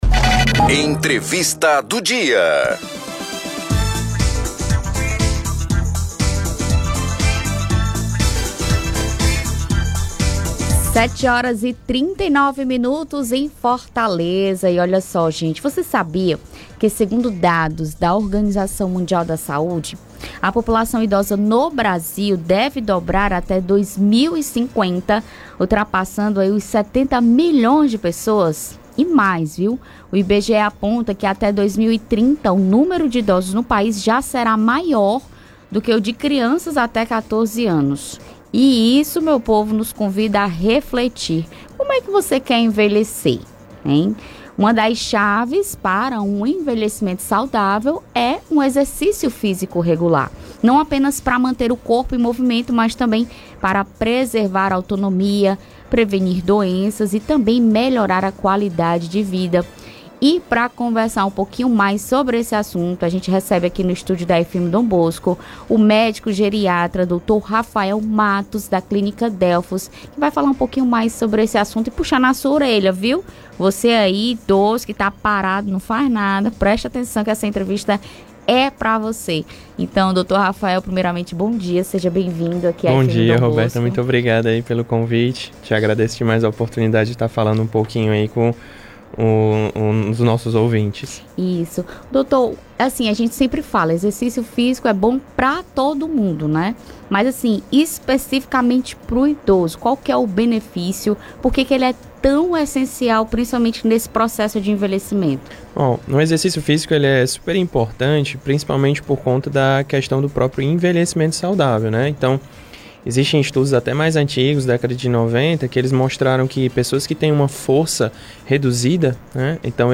Esse foi o tema da entrevista do programa Informativo Dom Bosco